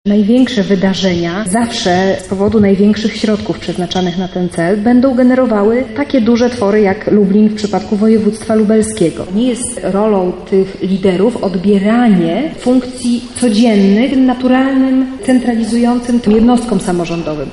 Na ten temat dyskutowali przedstawiciele instytucji kultury z różnych stron Polski podczas debaty Kultura samorządowa 25+
Debata odbyła się z okazji ćwierćwiecza istnienia polskich samorządów lokalnych.